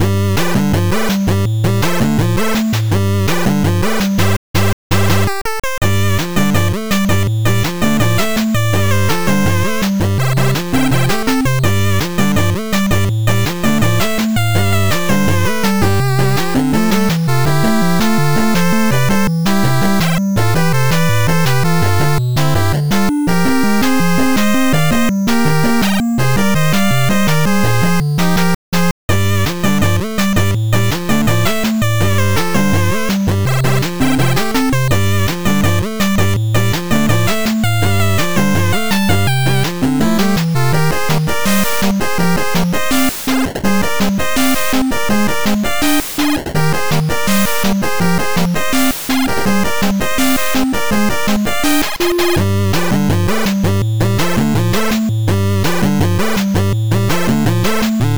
8bit music for action game.